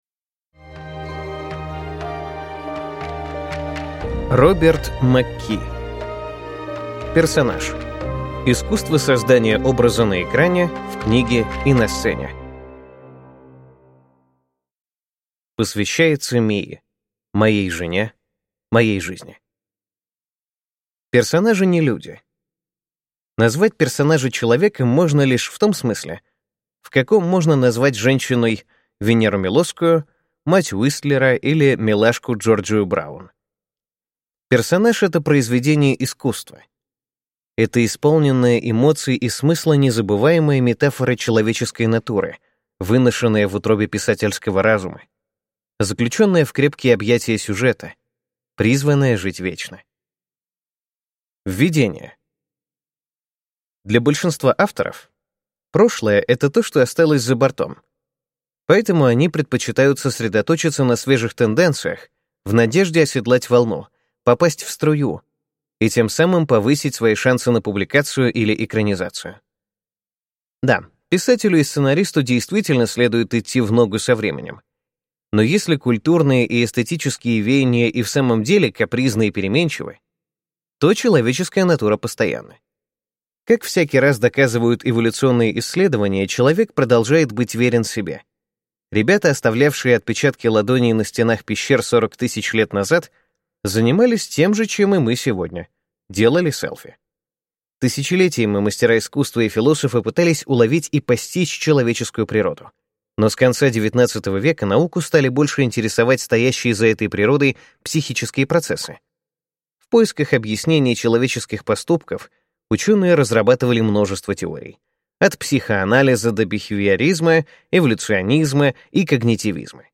Аудиокнига Персонаж. Искусство создания образа на экране, в книге и на сцене | Библиотека аудиокниг